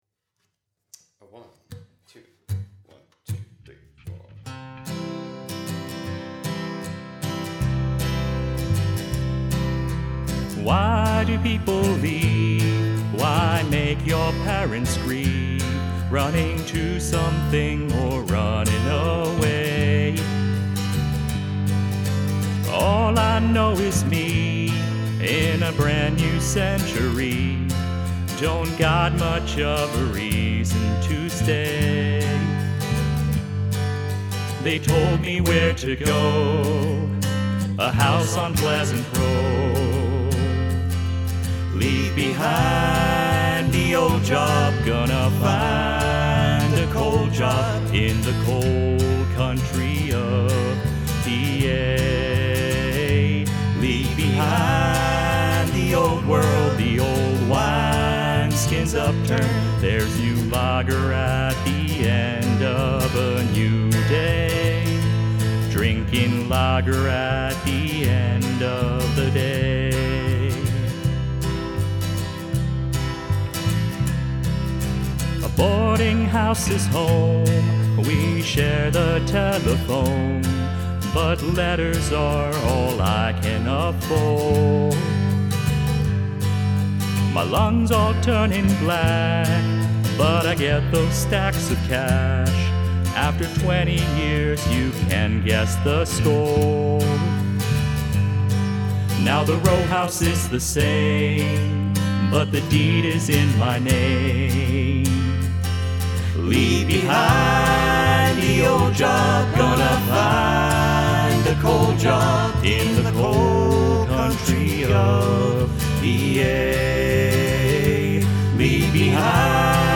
Must include a guest singing or speaking in another language